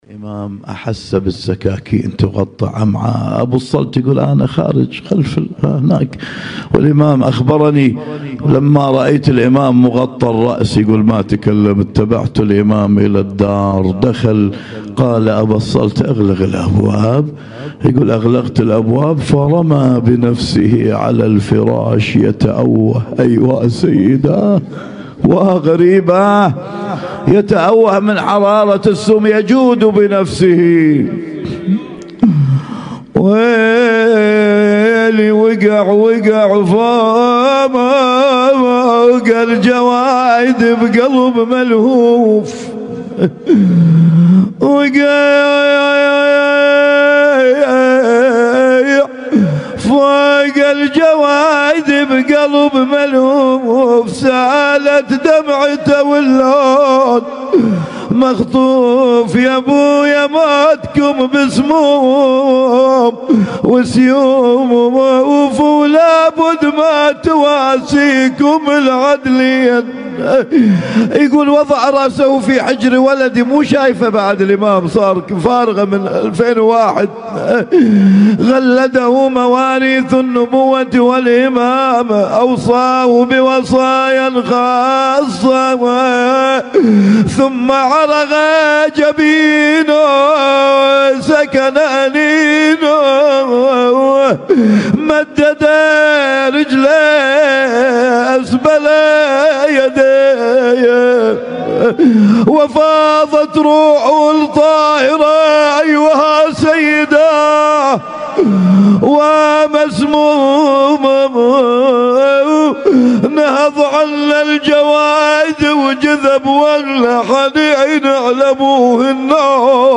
اسم التصنيف: المـكتبة الصــوتيه >> الصوتيات المتنوعة >> النواعي